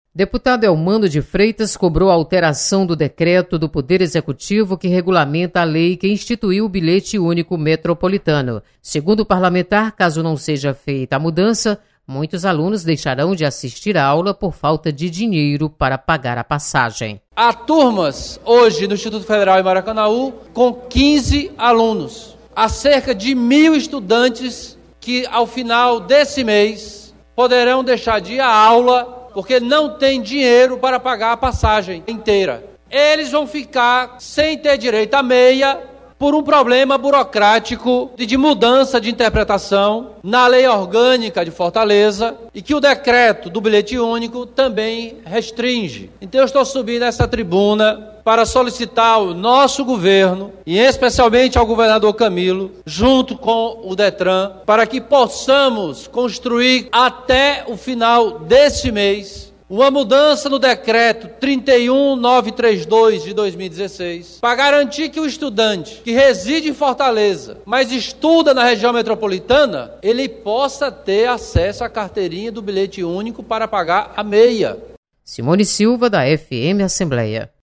Deputado Elmano de Freitas cobra alteração na legislação que regulamenta Bilhete Único Metropolitano para assegurar benefício aos estudantes da RMF.